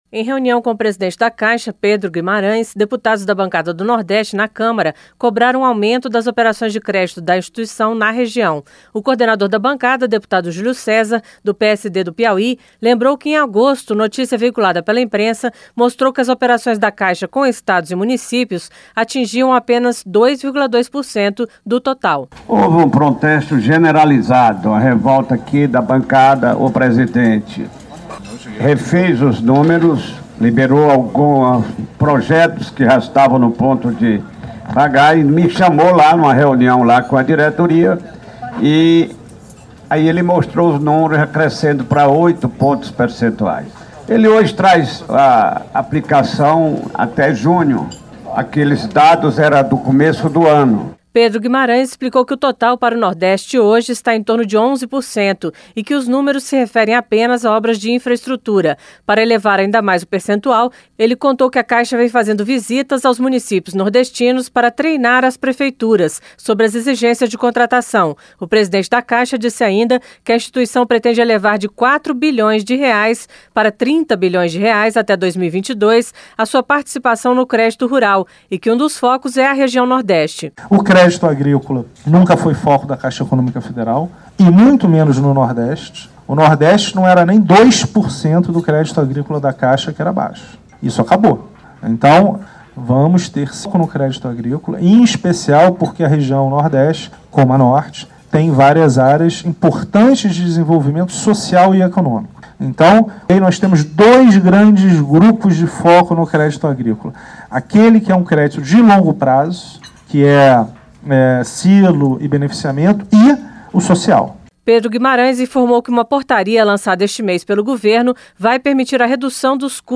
• Áudio da matéria